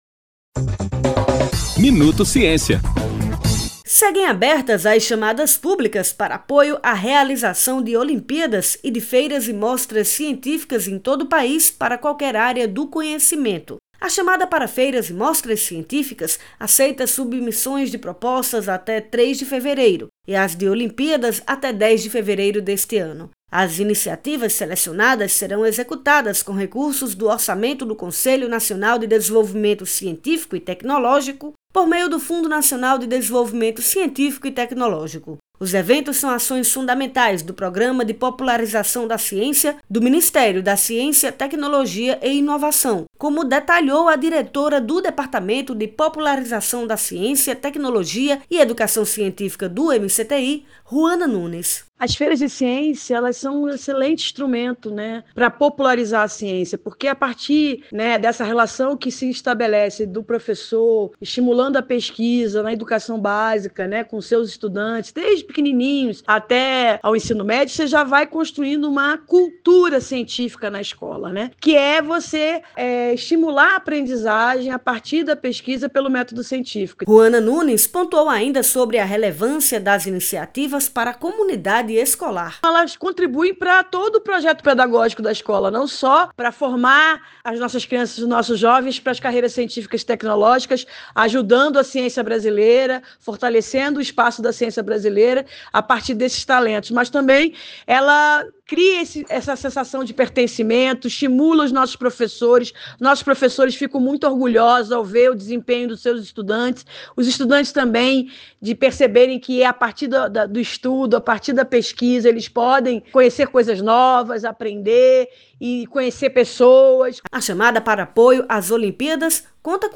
As ações selecionadas serão executadas com recursos do orçamento do CNPq, do Fundo Nacional de Desenvolvimento Científico e Tecnológico (FNDCT). Os detalhes com a repórter